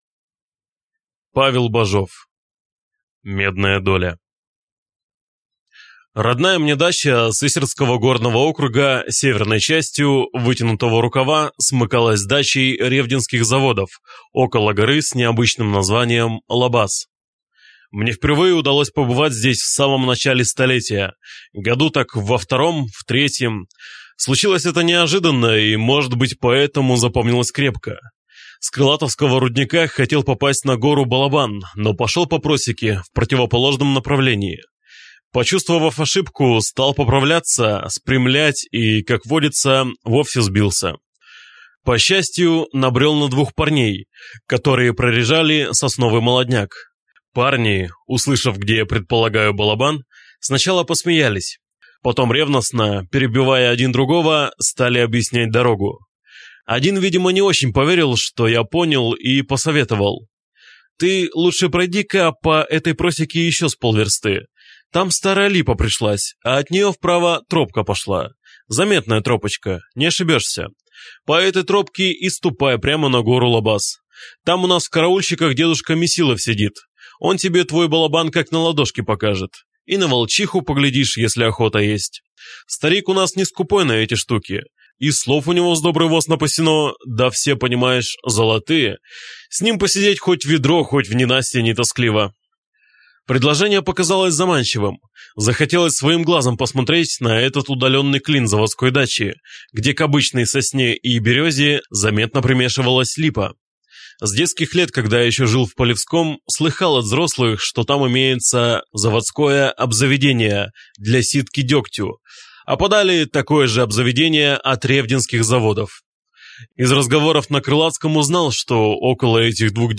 Аудиокнига Медная доля | Библиотека аудиокниг